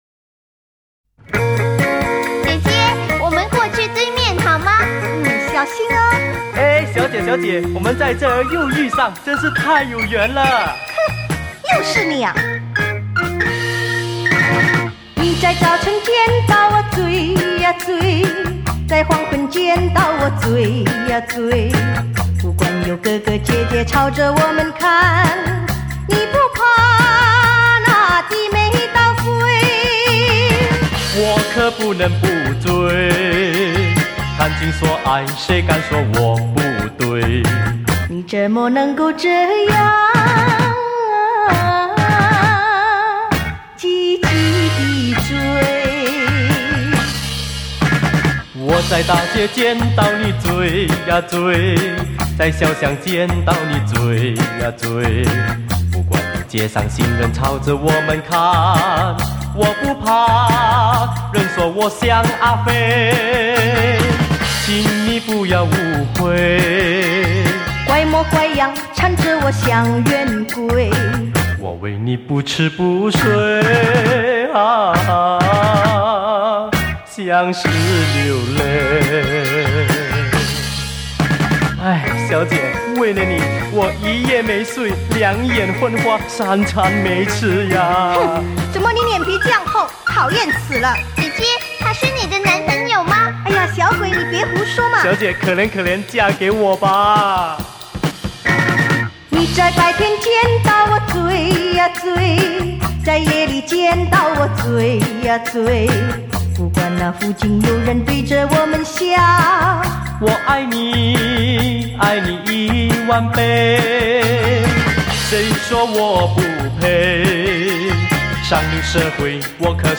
新潮风趣对唱歌曲